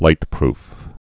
light·proof
(lītprf)